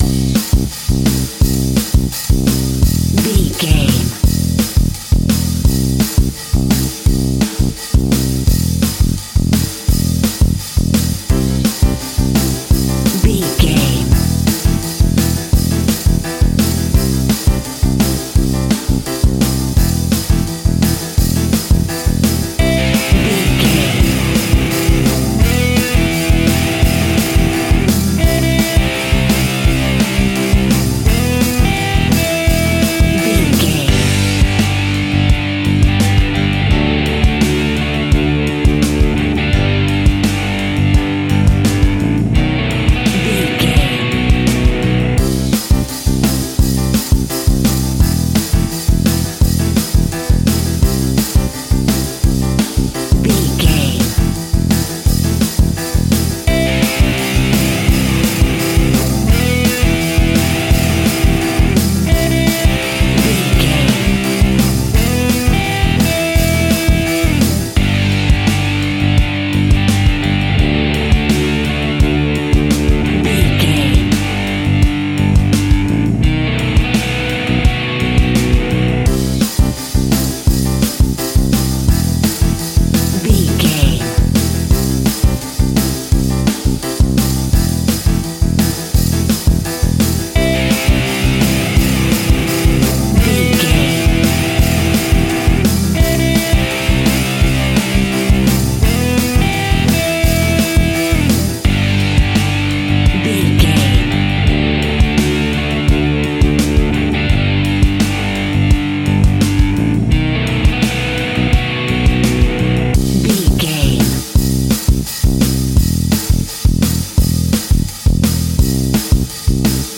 Epic / Action
Fast paced
Aeolian/Minor
pop rock
fun
energetic
uplifting
instrumentals
indie pop rock music
guitars
bass
drums
piano
organ